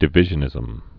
(də-vĭzhə-nĭzəm)